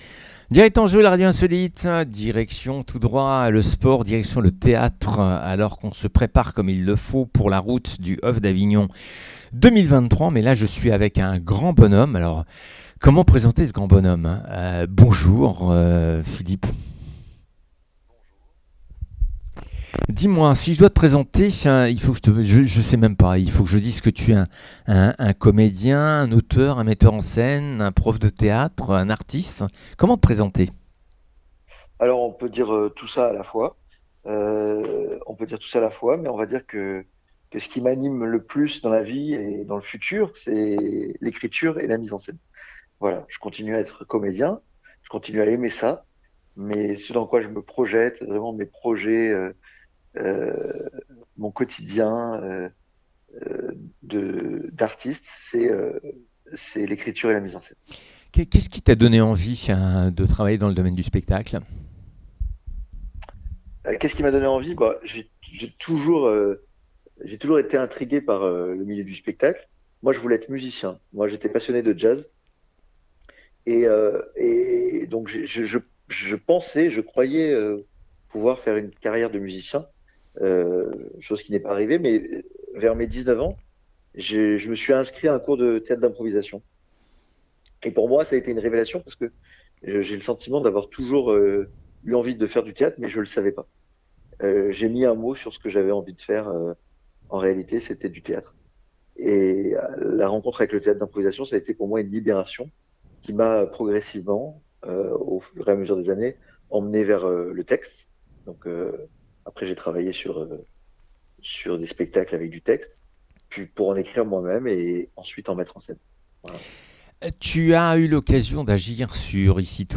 La radio grésille, la voix nasillarde du commentateur enfile comme des perles les noms des héros du tour de France sur un fil ininterrompu.